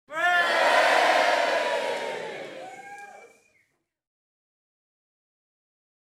cheer.wav